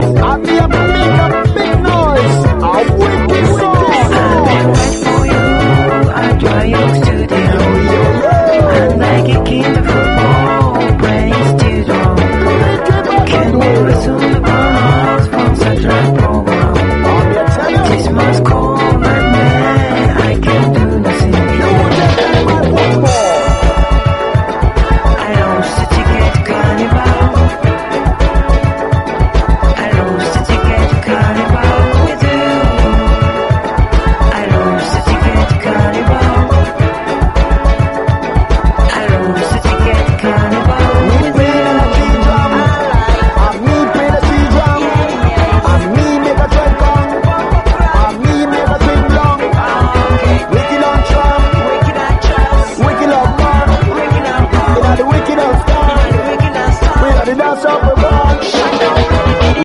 JAPANESE PUNK